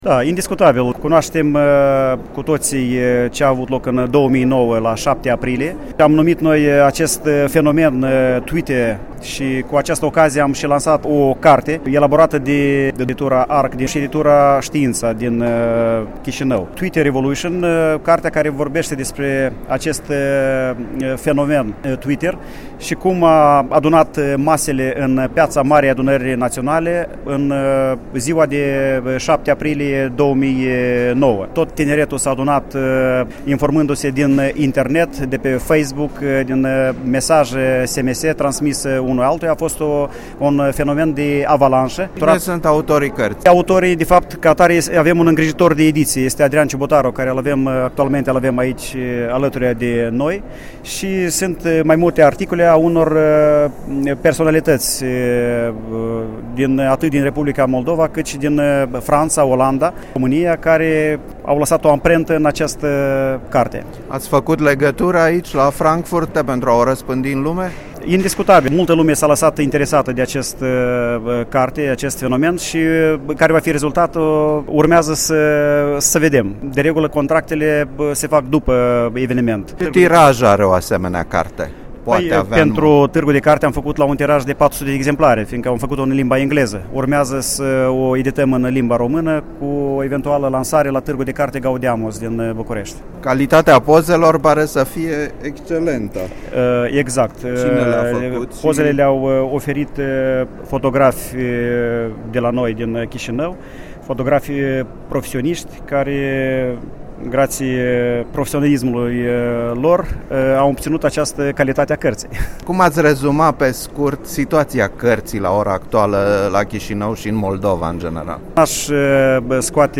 Interviu la Tîrgul de carte de la Frankfurt pe Main